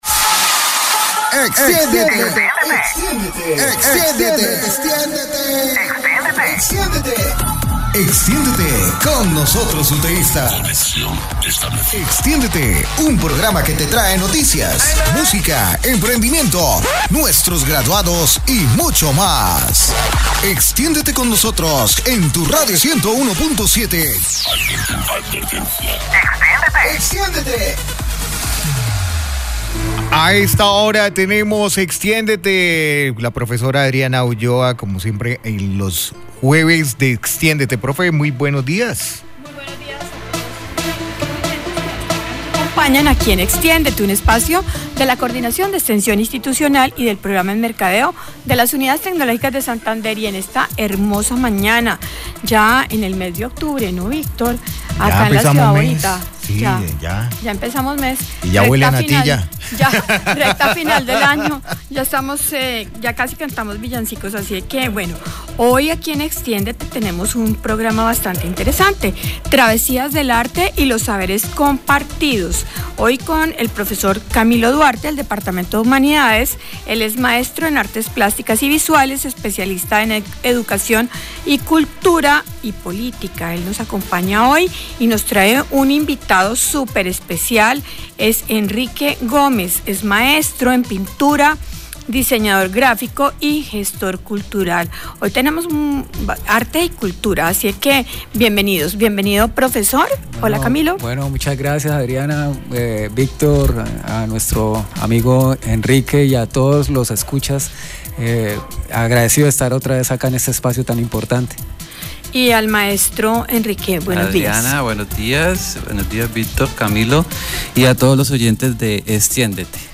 conversamos con el pintor y gestor cultural